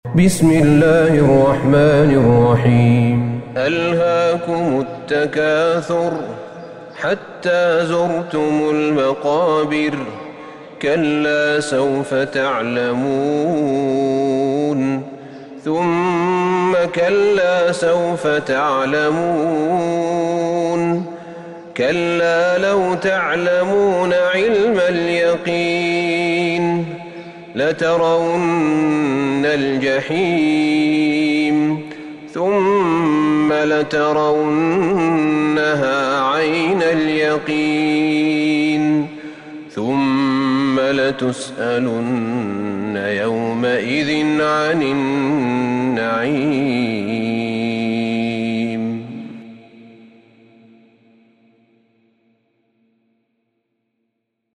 سورة التكاثر Surat At-Takathur > مصحف الشيخ أحمد بن طالب بن حميد من الحرم النبوي > المصحف - تلاوات الحرمين